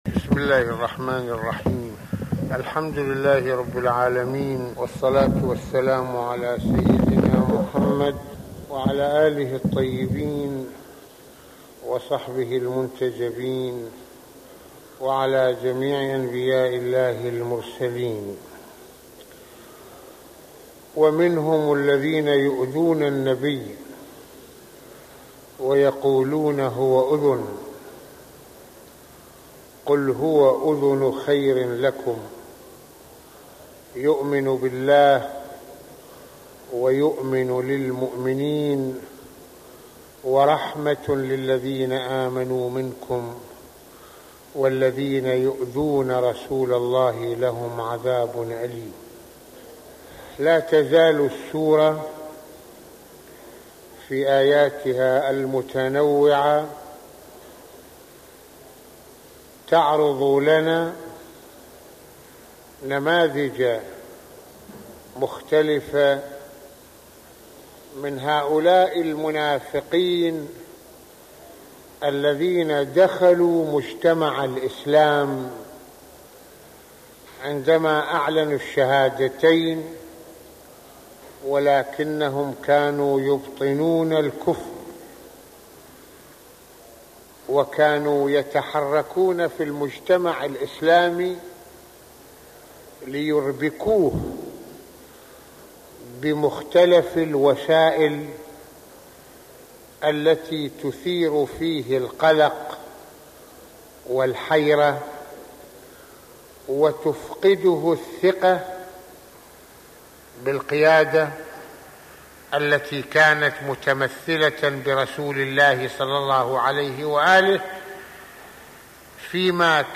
- يتحدث العلامة المرجع السيد محمد حسين فضل الله "رض" في هذا المحاضرة القرآنية عن وضع المسلمين في مواجهة المشركين ، وضرورة إعداد العدة الكافية والجهوزية التامة لافتاً إلى حكمة النبي (ص) وقيادته الرشيدة ، وما علينا نحن أن نتعلمه من أسلوبه لجهة معالجة أمورنا وتدبيرها في سياق المواجهة العامة في الحياة ...